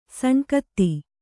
♪ saṇkatti